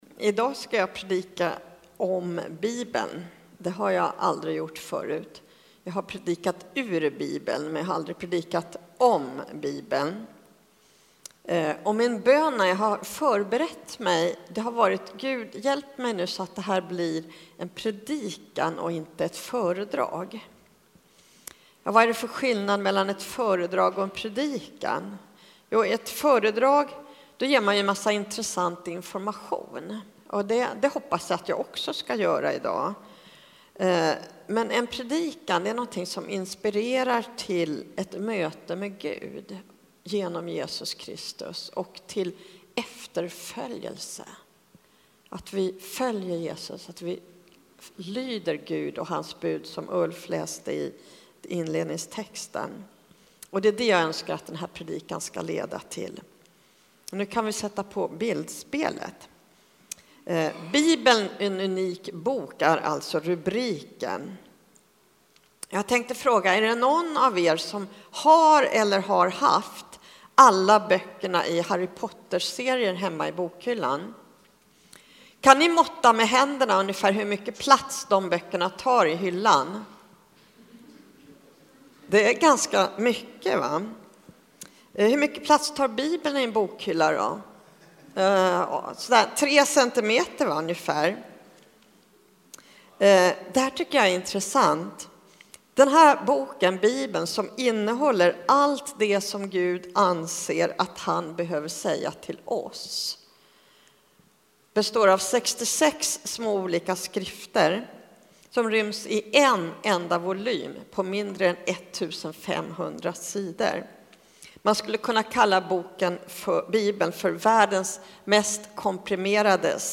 Del 3 i predikoserien Gör tron någon skillnad?